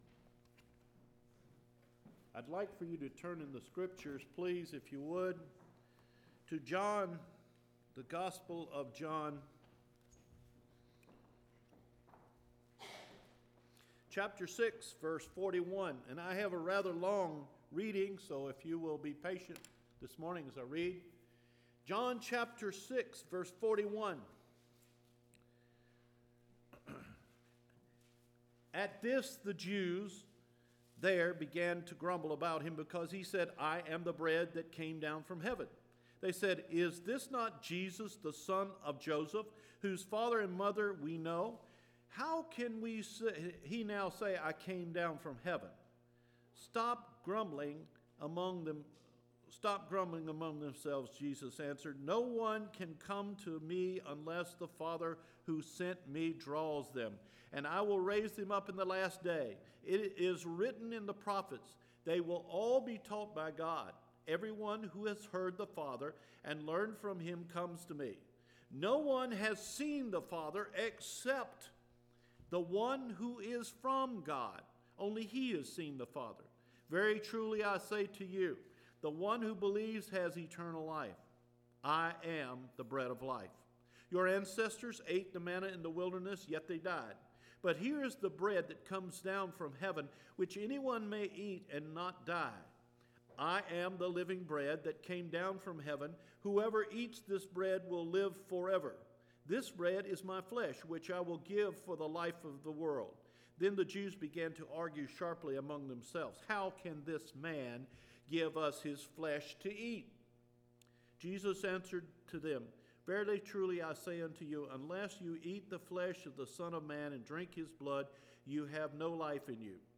The Calling and the Response – January 20 Sermon